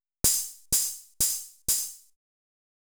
HIHAT014_DISCO_125_X_SC3.wav